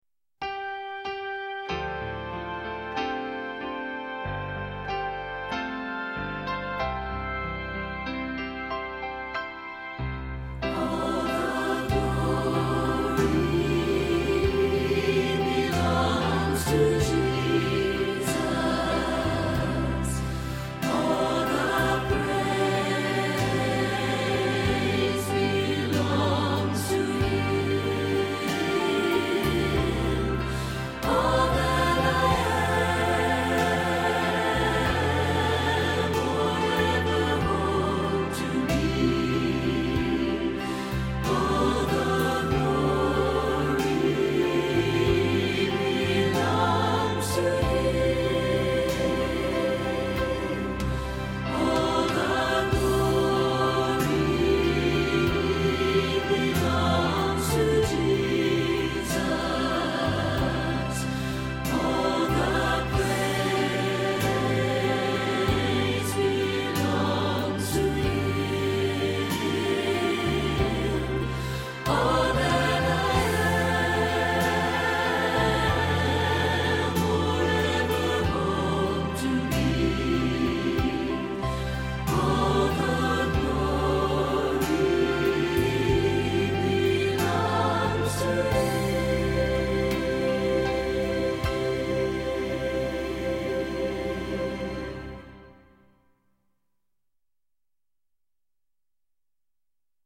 Choir Music to practice with